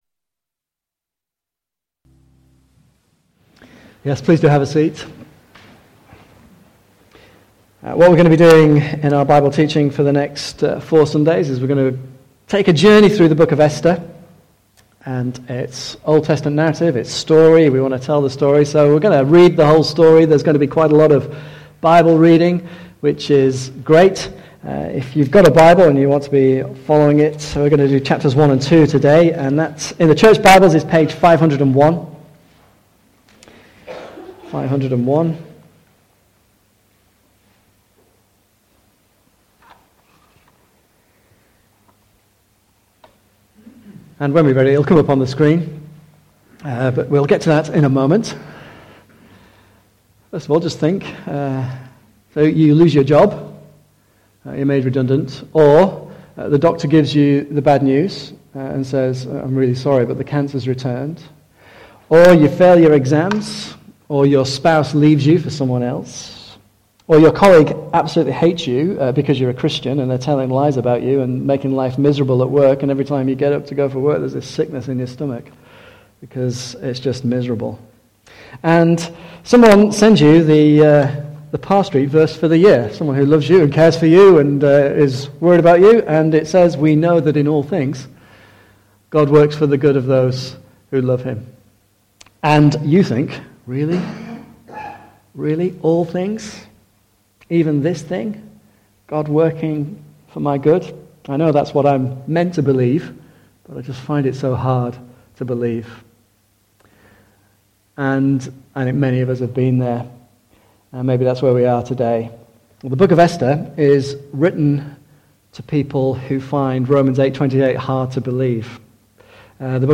Service Type: Morning Service
Apologies for the quality of the audio in this recording. The input volume on the recorder was set too high.